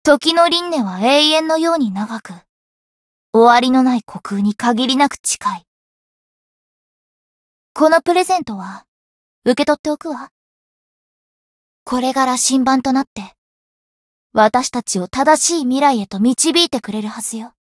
灵魂潮汐-神纳木弁天-春节（送礼语音）.ogg